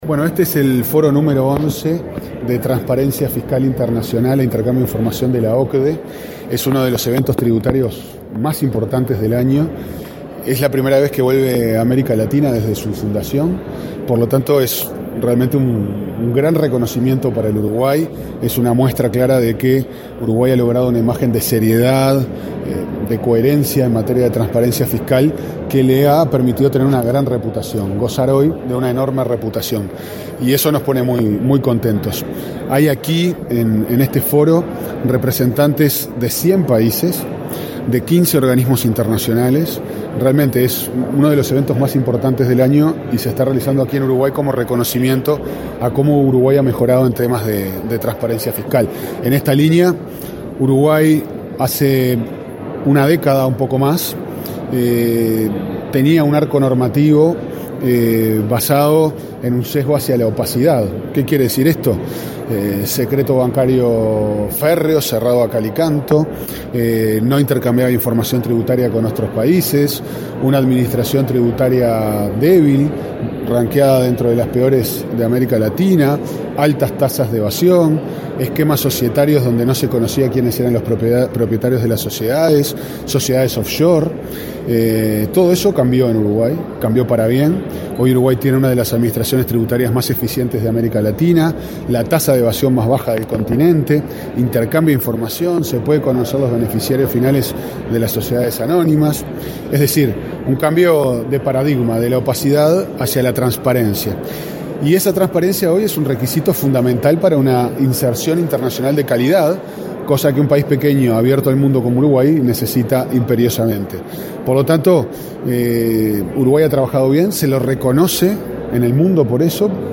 Uruguay varió de un sistema fiscal que promovía la opacidad en la década de 1990 a un esquema que favorece la transparencia, afirmó el subsecretario de Economía, Pablo Ferreri, en la XI Reunión del Foro Global sobre Transparencia e Intercambio de Información con Fines Fiscales que se realiza entre este 20 y 22 en Punta del Este.